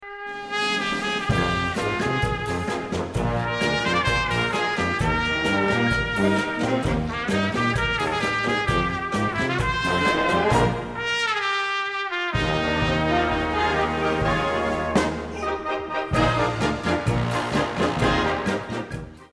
Besetzung: Blasorchester
Mindestbesetzung: ab Big Band Besetzung.